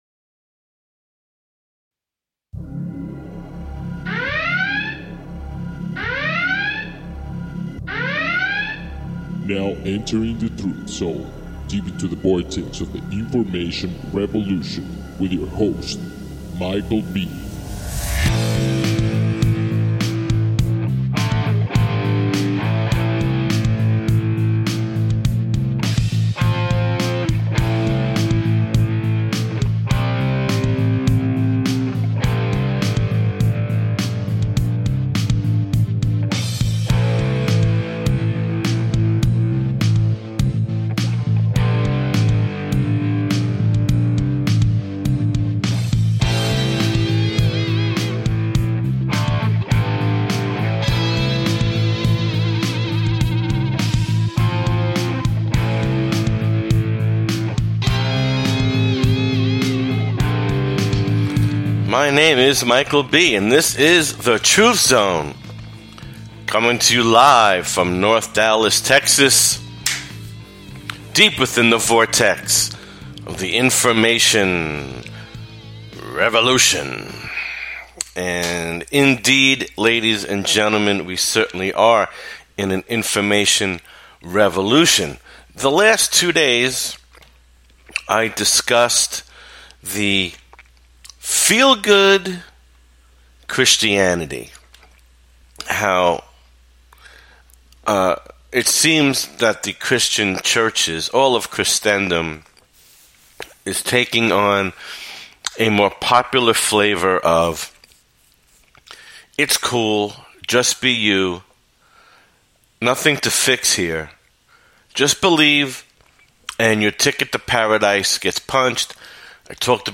The Truth Zone is in your face radio and not for the weak of heart.